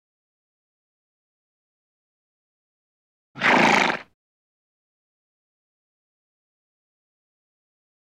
Snort | Sneak On The Lot
Horse Blow, Single, Close Perspective.